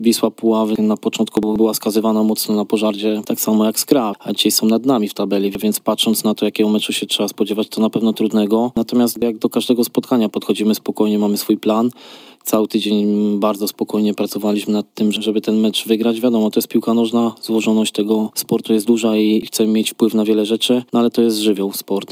na konferencji prasowej